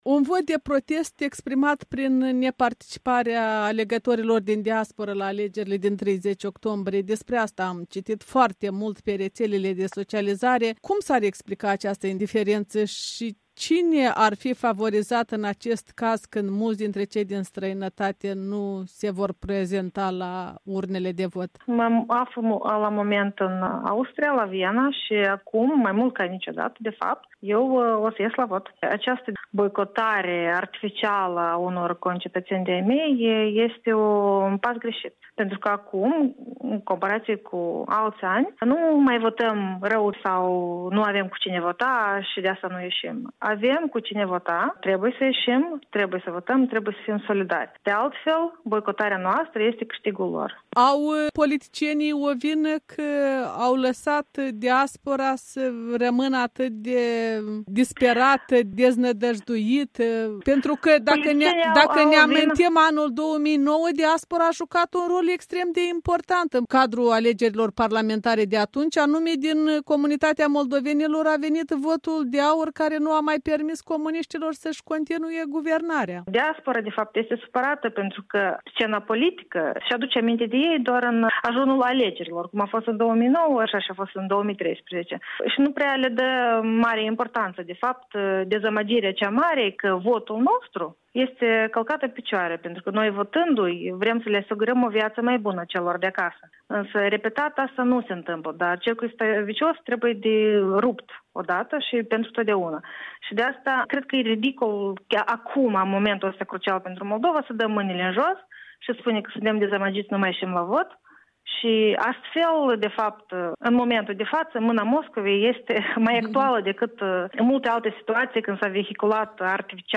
Interviu pe teme electorale cu o moldoveancă stabilită la Viena în Austria.